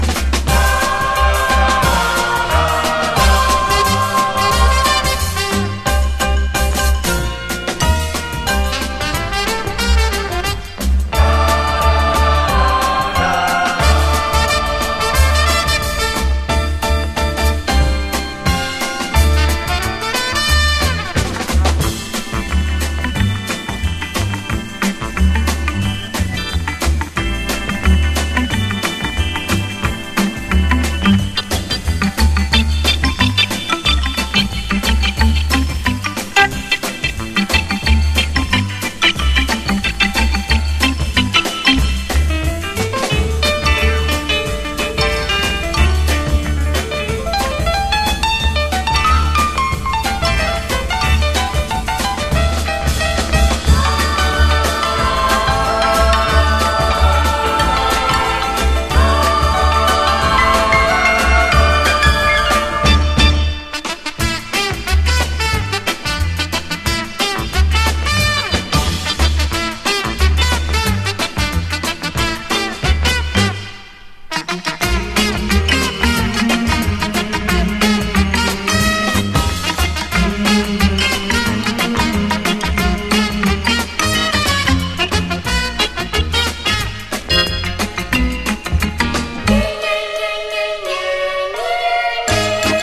SOUL / SOUL / 70'S～ / JAPANESE DISCO / DISCO (JPN)
はホーンとコシのあるグルーヴがフロアを揺るがす名曲！